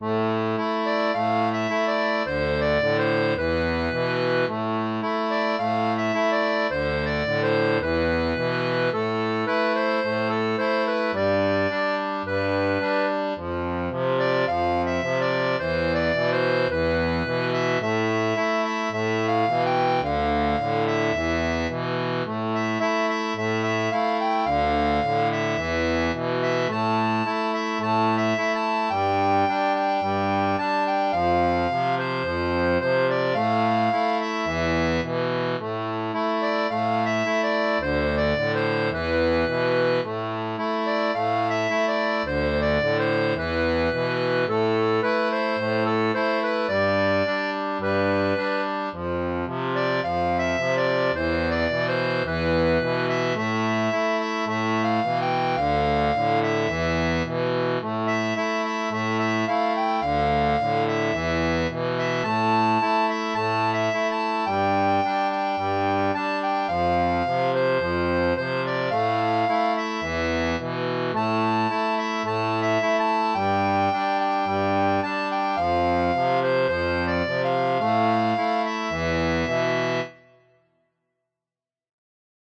Musique cubaine